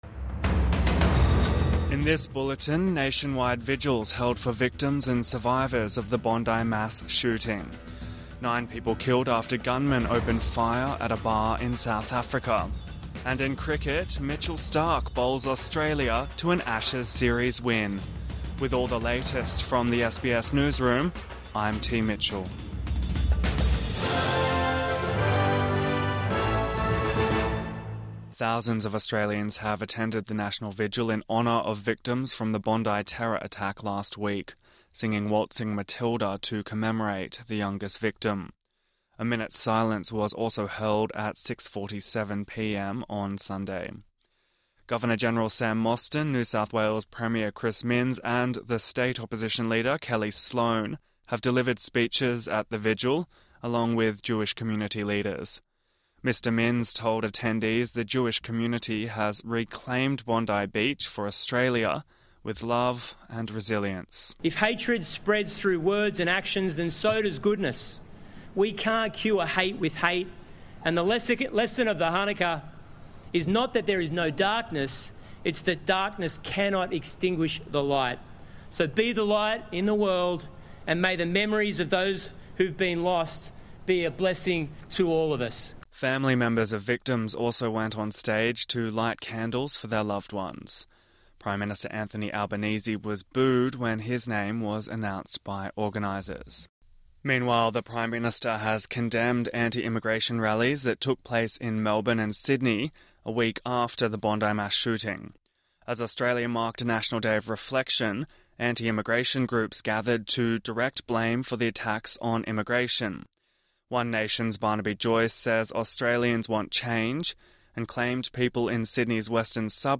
Nationwide vigils for Bondi Beach victims and survivors | Morning News Bulletin 22 December 2025